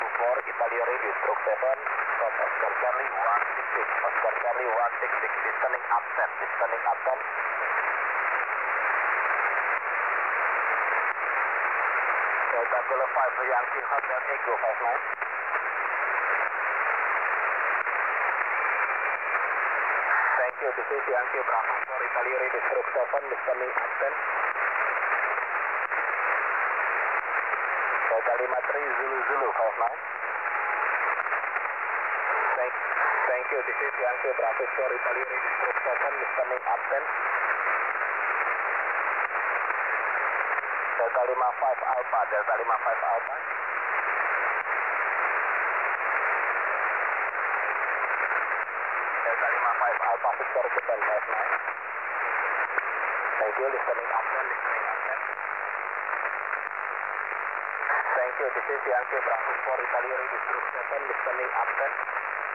14MHz SSB